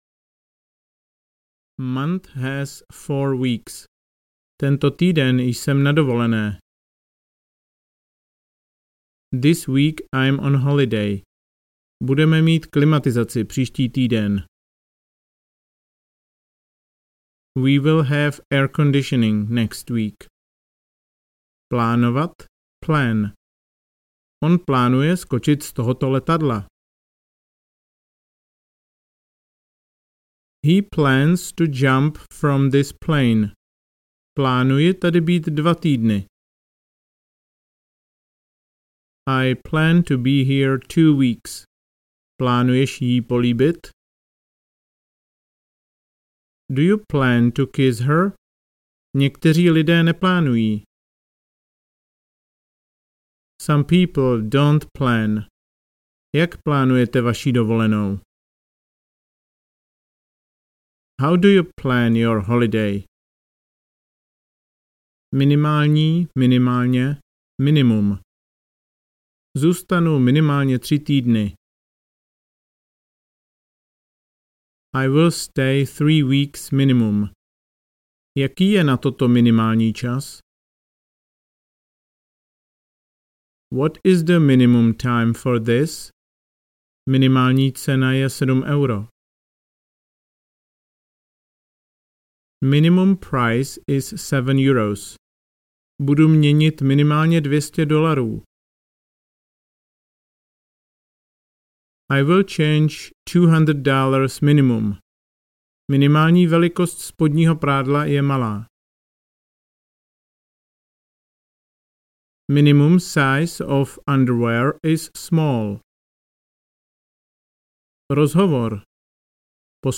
Začátečník v restauraci audiokniha
Ukázka z knihy